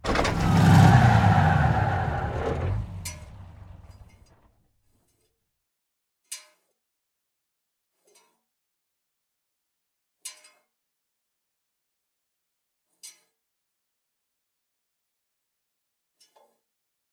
tank-engine-stop-2.ogg